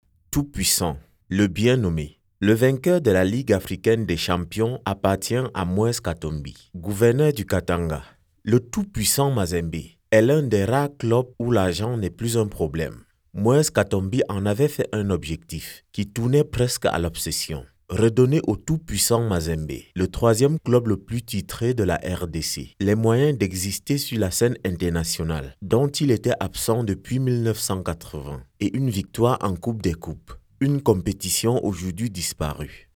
Never any Artificial Voices used, unlike other sites. All our voice actors are premium seasoned professionals.
Adult (30-50)